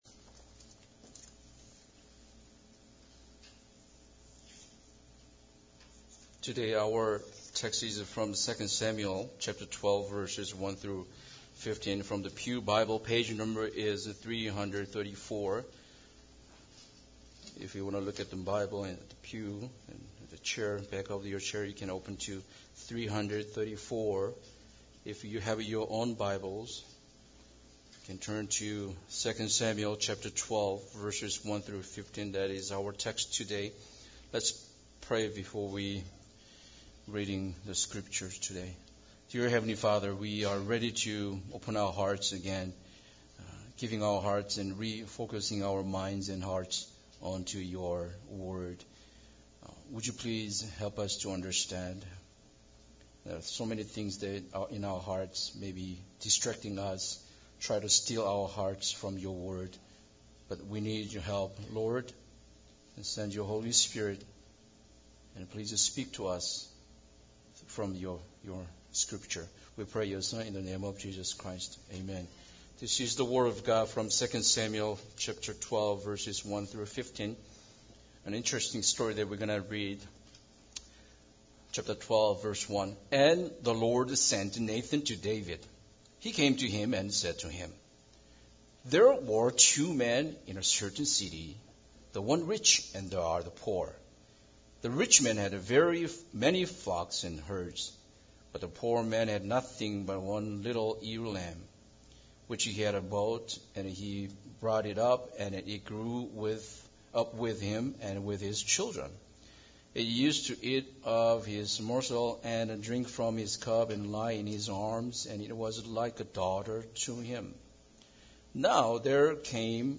Passage: 2 Samuel 12:1-15 Service Type: Sunday Service Bible Text